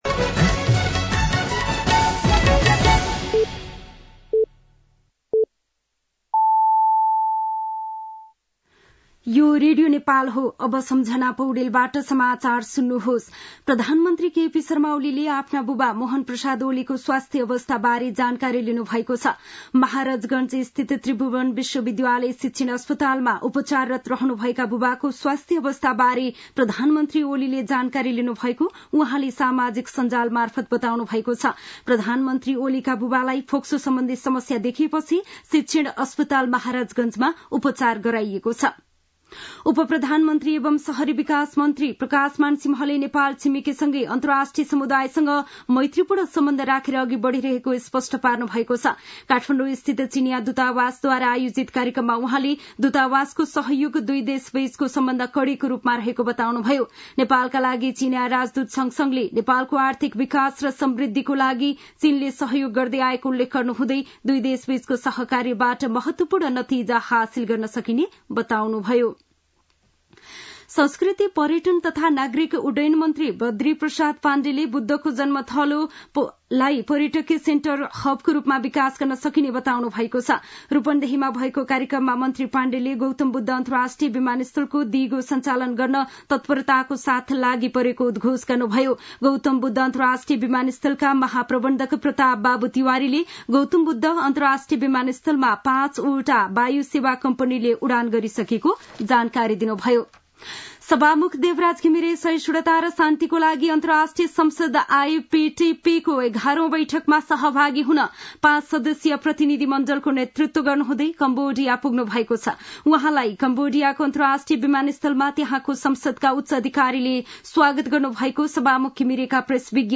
दिउँसो ४ बजेको नेपाली समाचार : ९ मंसिर , २०८१
4-pm-nepali-news-1.mp3